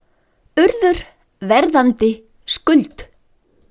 Listen to pronunciation: Urður Verðandi Skuld